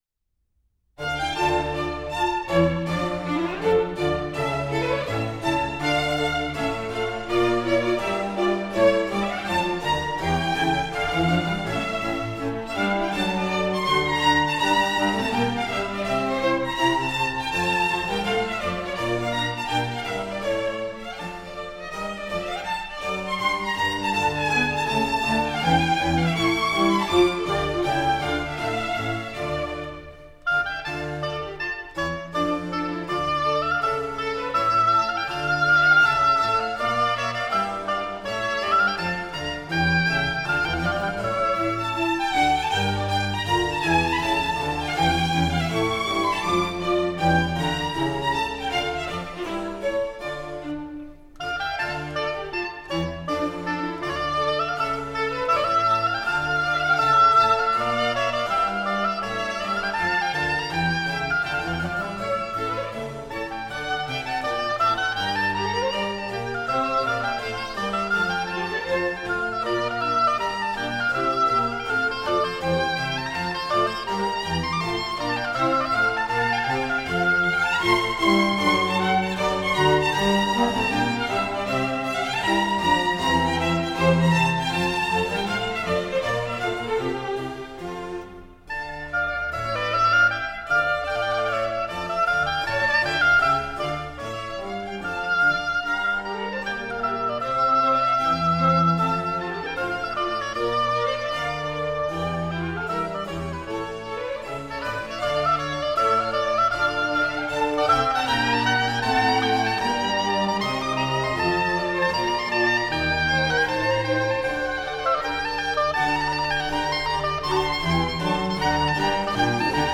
Heinz Holliger, oboe.
I Musici.